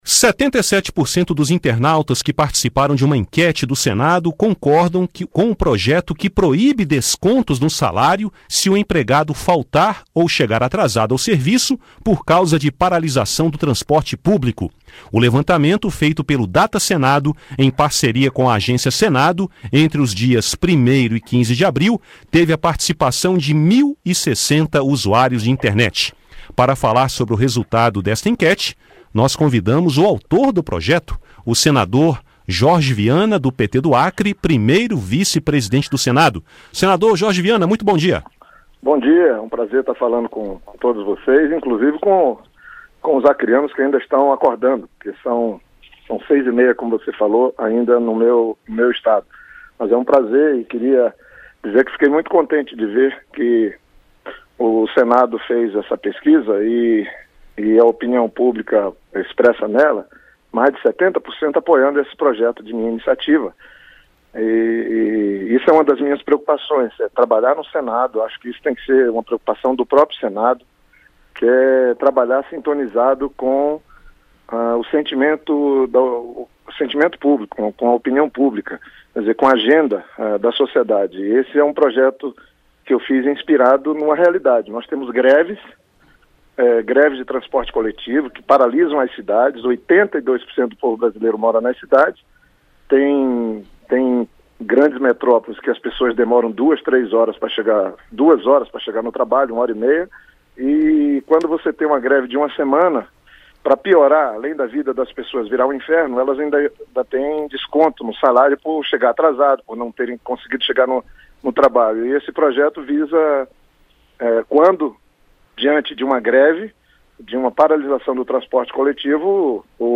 Jorge Viana falou sobre o assunto no programa Conexão Senado, da Rádio Senado. O senador falou também da edição do Fórum Mundial da Água que acontecerá em Brasília em 2018.